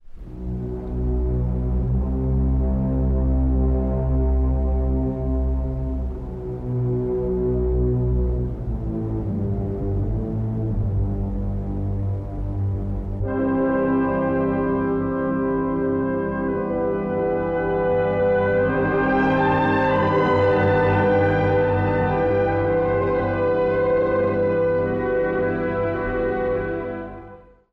（序奏） 古い音源なので聴きづらいかもしれません！（以下同様）
低弦が静かに呟き、金管が陰鬱な色を添える冒頭。
空をゆっくりと黒雲が流れて、冷たい大気の中で音が溶け込んでいくよう。
やがてVnのメロディーが、広大なロシアの情景をイメージさせる如く現れます。